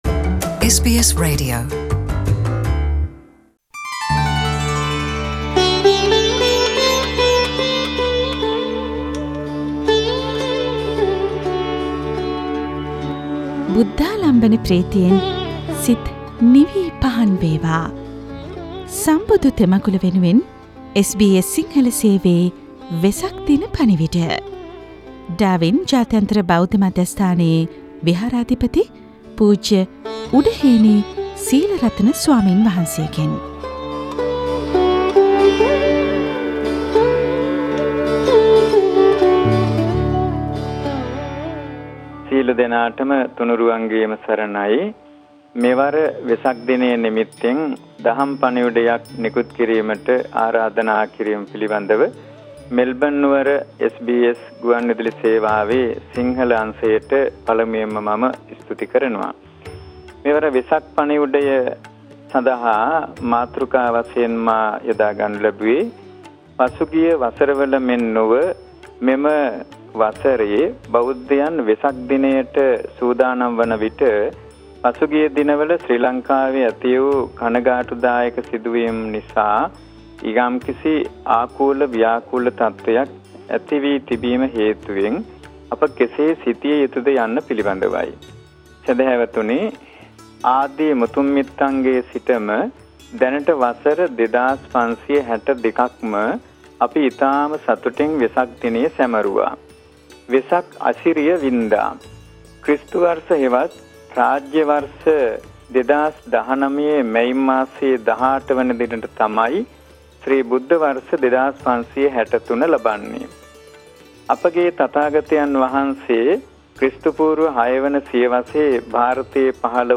ආගම් අතර වෛරය ජනිත කරන දෑ අතීත භාරතයේත් සිදුවුනා එත් බෞද්ධයින් මෙත් සිත පැතුරවුවා : වෙසක් දහම් පණිවිඩය